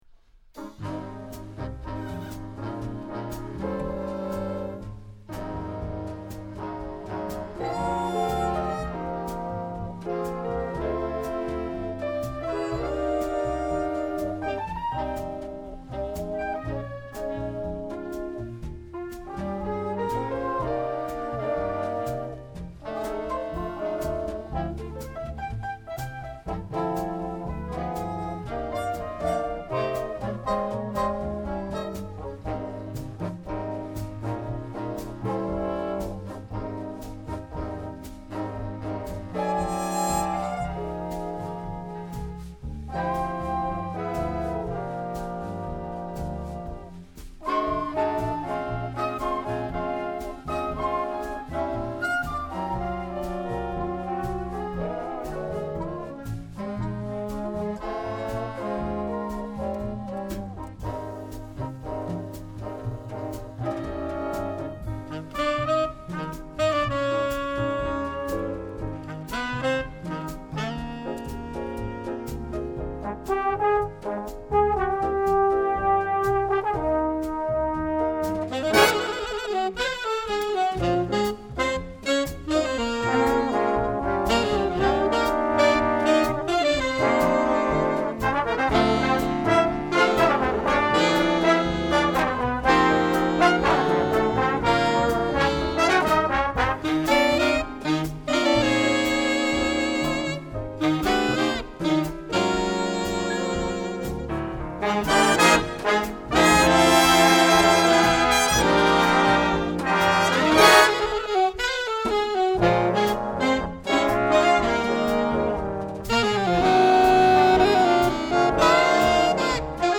Store/Music/Big Band Charts/ARRANGEMENTS
Beautiful Bossa Nova